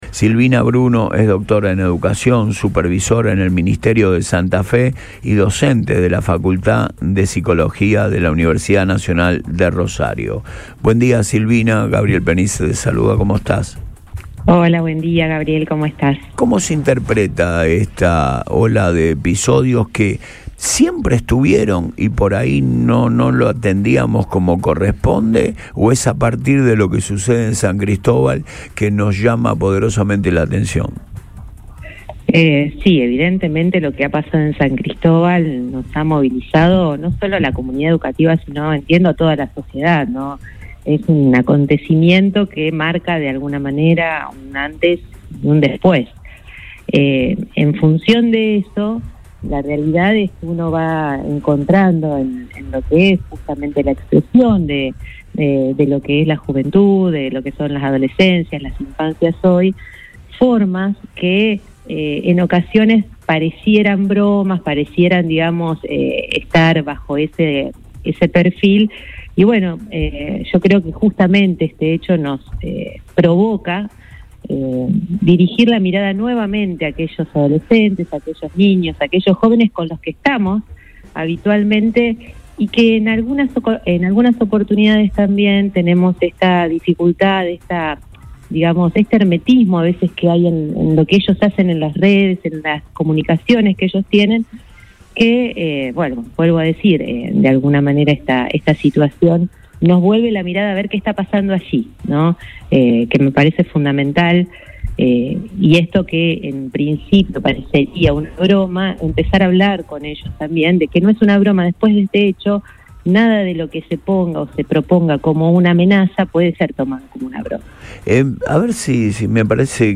“Hay un mecanismo de actuación claro que se pone en marcha ante cada situación”, concluyó, en diálogo con el programa radial Antes de Todo por Radio Boing.